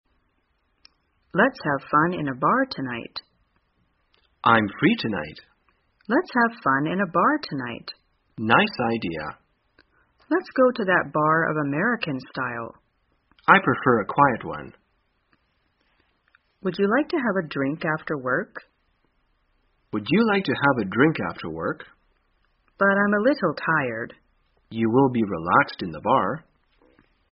在线英语听力室生活口语天天说 第268期:怎样计划去酒吧的听力文件下载,《生活口语天天说》栏目将日常生活中最常用到的口语句型进行收集和重点讲解。真人发音配字幕帮助英语爱好者们练习听力并进行口语跟读。